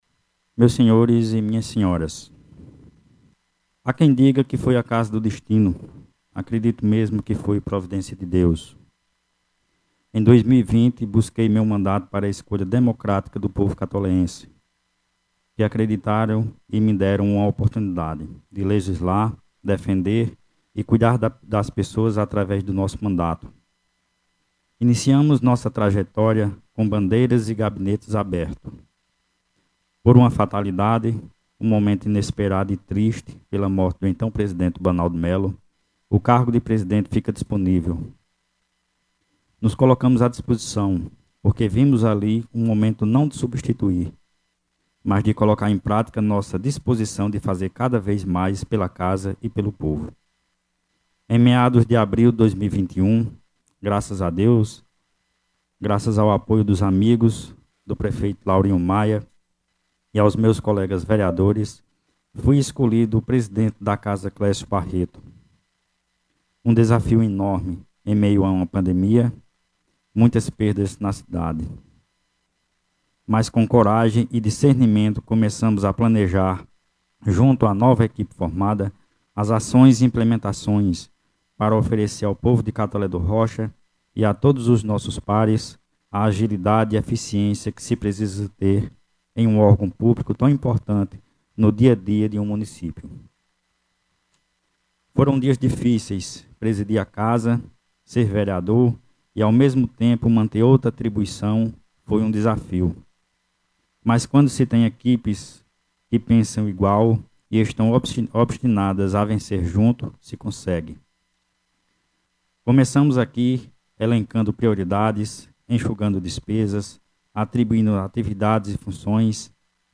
O Vereador Daniel Nunes Cavalcante, na manhã de domingo(1º), presidiu a solenidade de transmissão de cargo, onde foram conduzidos para tomar posse aos cargos de Presidente: Gentil Lira Barreto; Vice-presidente: Garber Jardel Cavalcante Diniz; 1º Secretário: Themístoclys Marinho Barreto e 2º Secretário: Humberto Ferreira Maia, onde foram eleitos para a mesa diretora, em 07 de Janeiro de 2021, para o biênio 2023/2024.
Daniel-Cavalcante-Pronunciamento.mp3